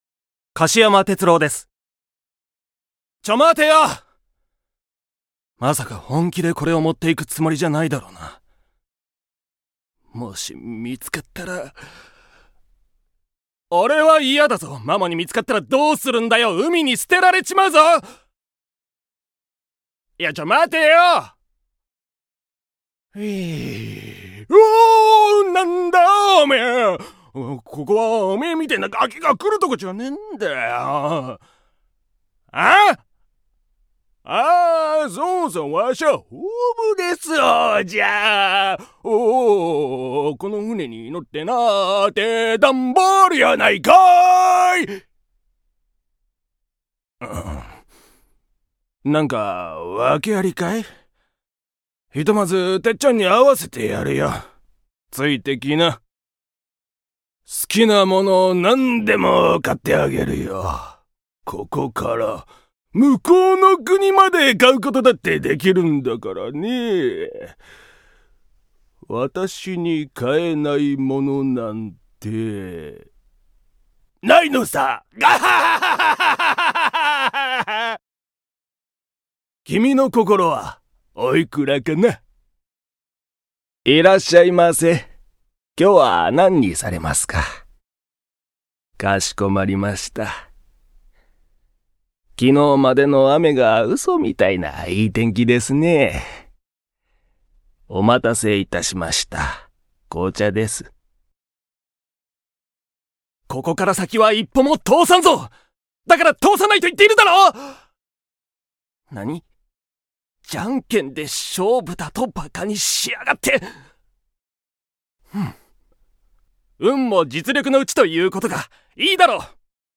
方言　 ： 関西弁
◆台詞